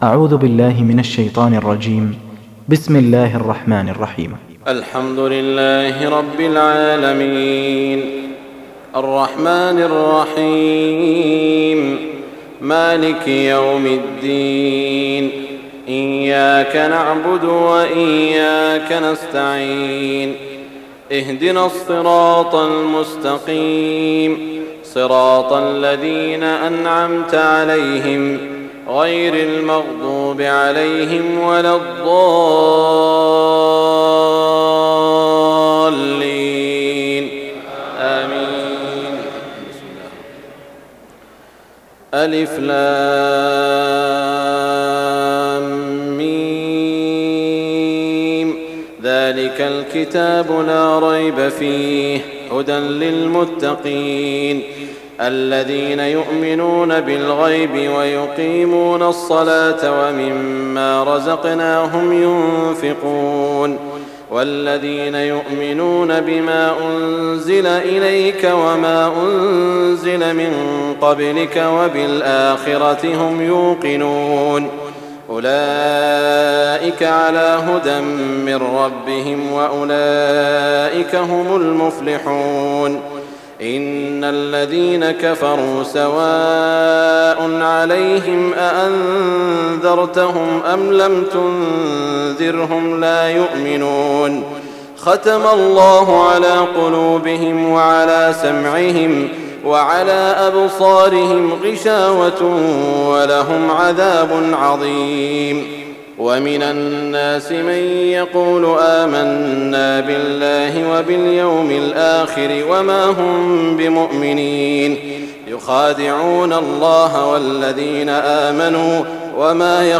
تهجد ليلة 21 رمضان 1419هـ من سورة البقرة (1-91) Tahajjud 21 st night Ramadan 1419H from Surah Al-Baqara > تراويح الحرم المكي عام 1419 🕋 > التراويح - تلاوات الحرمين